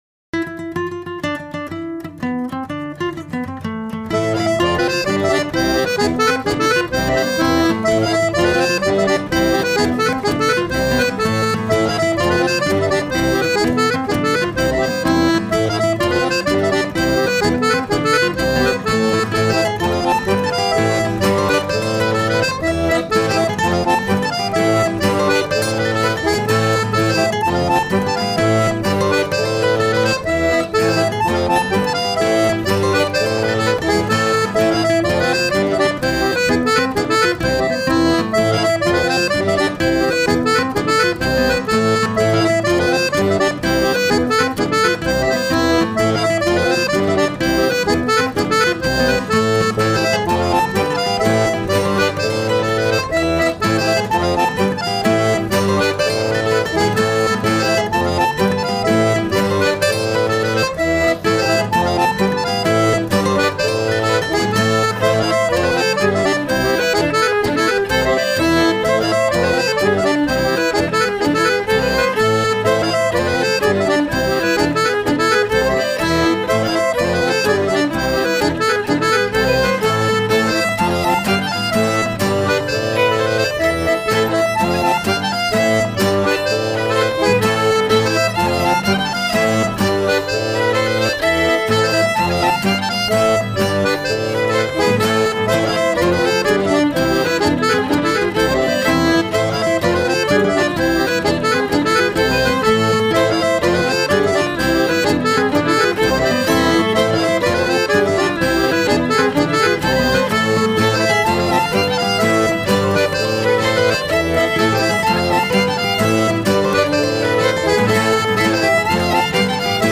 Musiques d'Alsace et d'Ailleurs
jig